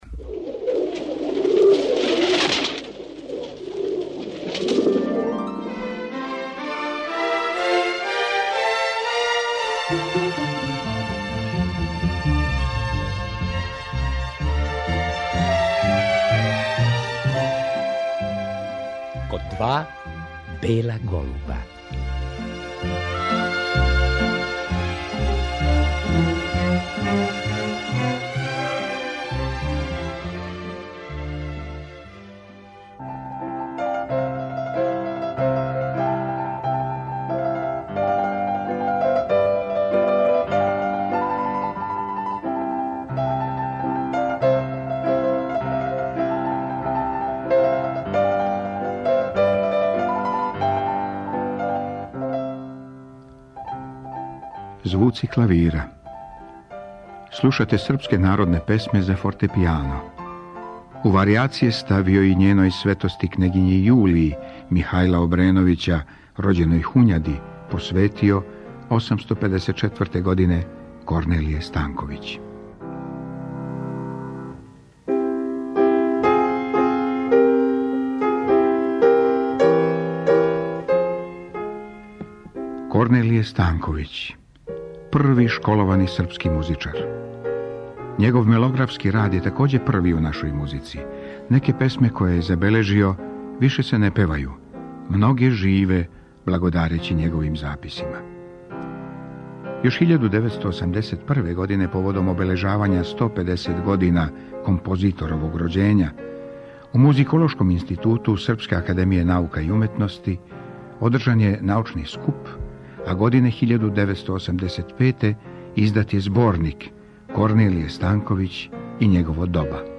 Поводом годишњице смрти Корнелија Станковића, композитора, пијанисте и хоровође, 5. априла 1865. године, чућемо емисију из 1995. године. Гост је била музиколог